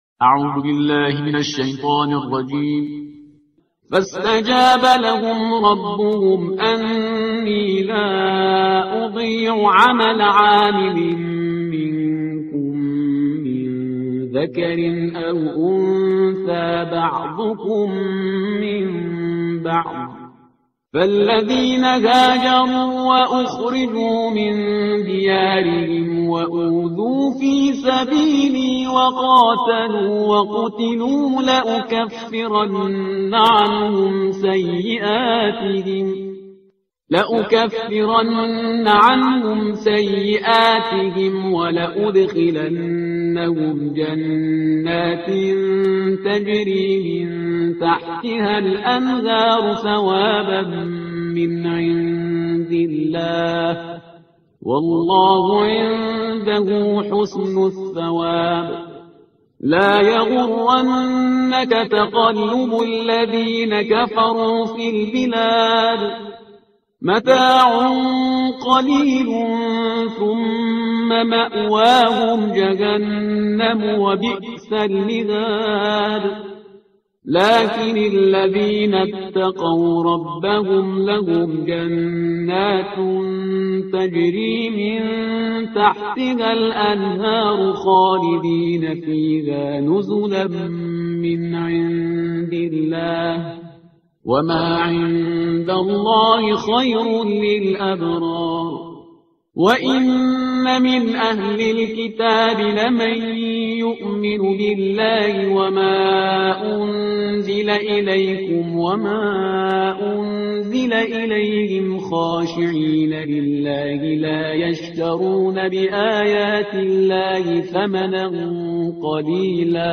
ترتیل صفحه 76 قرآن